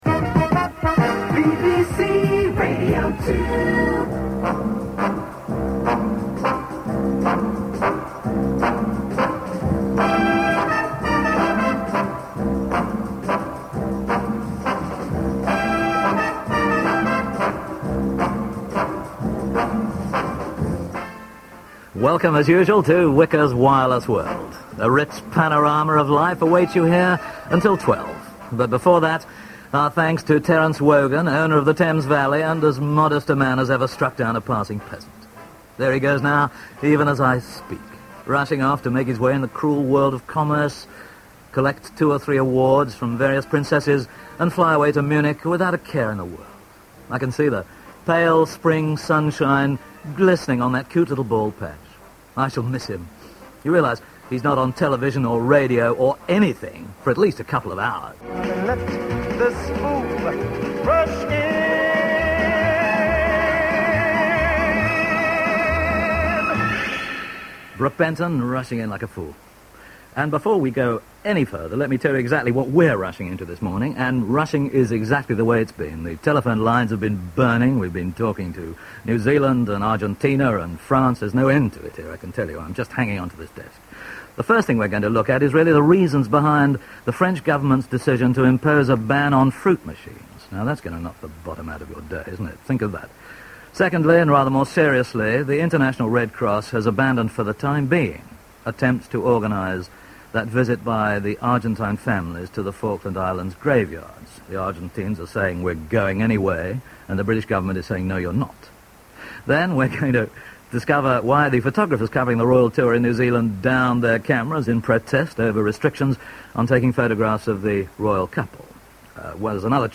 Best known for his television appearances on Tonight and Whicker’s World broadcaster and journalist Alan Whicker, who died yesterday, made only occasional forays onto the radio. Here he is in 1983 sitting in for a holidaying Jimmy Young on BBC Radio 2.